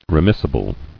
[re·mis·si·ble]